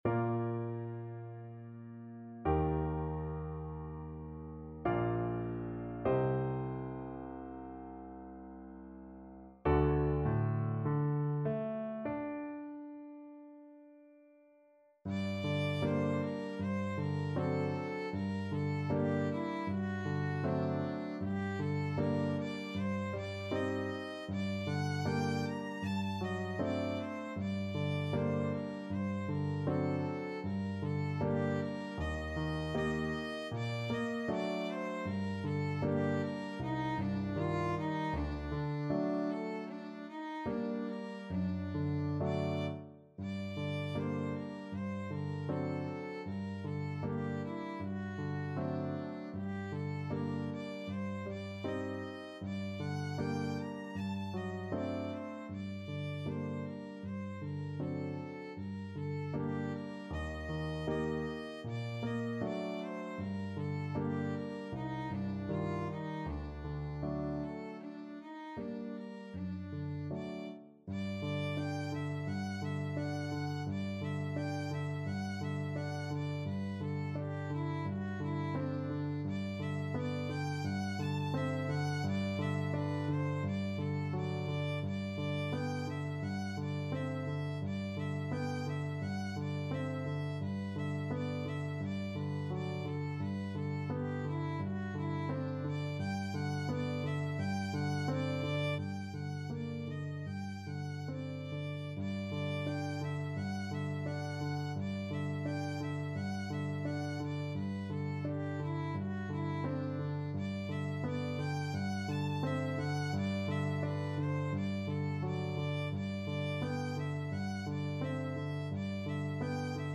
Violin
Bb major (Sounding Pitch) (View more Bb major Music for Violin )
4/4 (View more 4/4 Music)
Largo
Classical (View more Classical Violin Music)